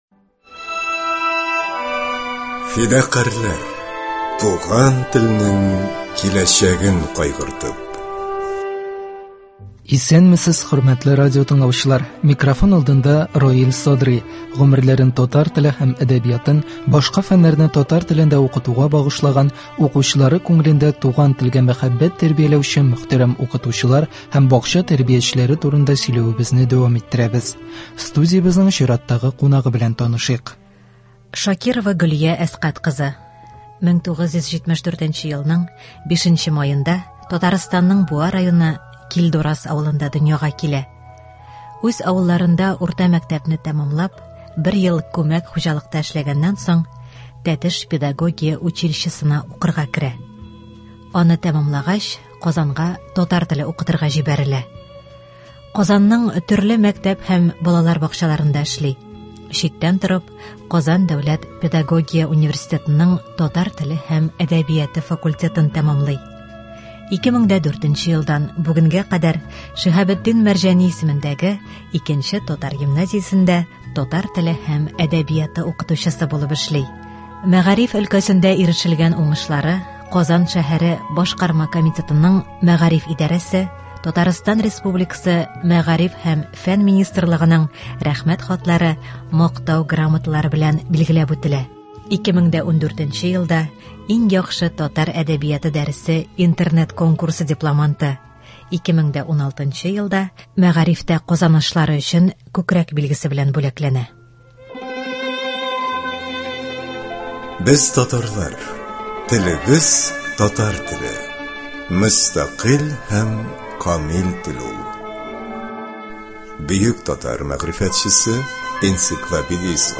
Гомерләрен татар теле һәм әдәбиятын, башка фәннәрне татар телендә укытуга багышлаган, укучылары күңелендә туган телгә мәхәббәт тәрбияләүче мөхтәрәм укытучылар һәм бакча тәрбиячеләре турында сөйләвебезне дәвам иттерәбез. Студиябезнең чираттагы кунагы